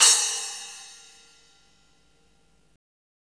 Index of /90_sSampleCDs/Roland L-CDX-01/CYM_Cymbals 1/CYM_Cym Modules
CYM SPLAS08R.wav